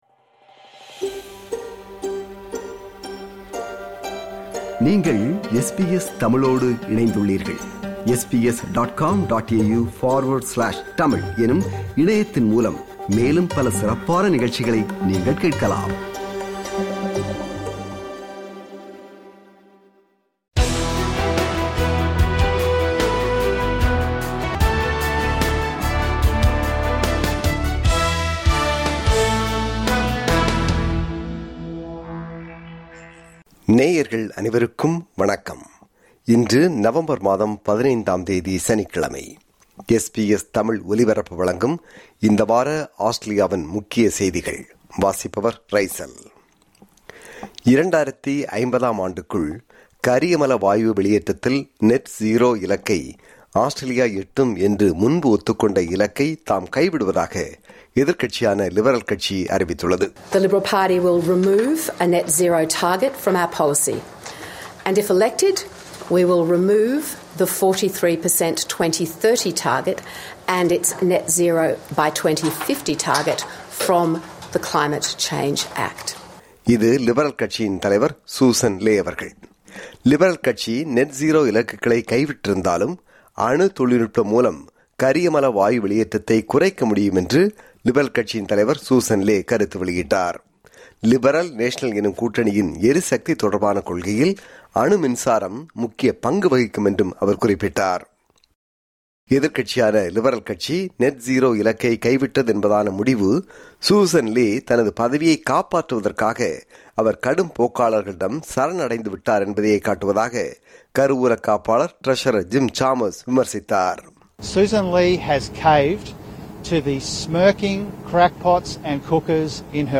SBS தமிழ்